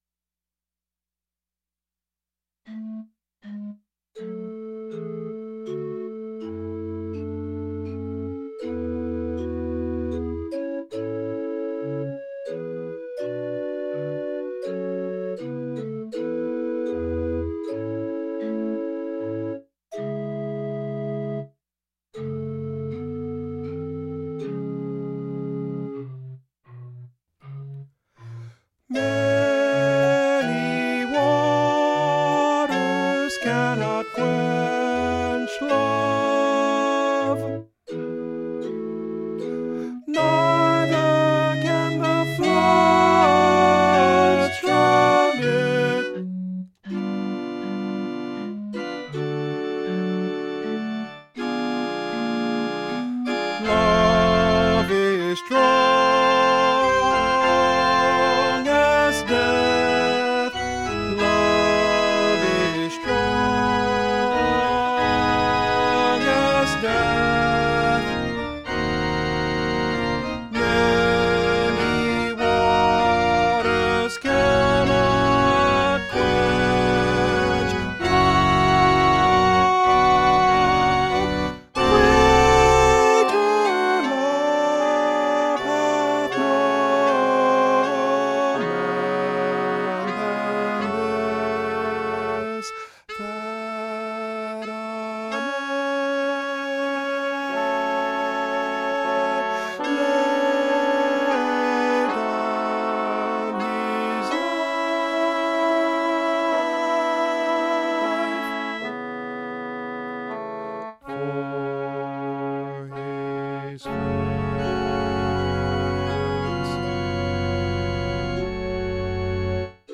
Soprano   Instrumental | Downloadable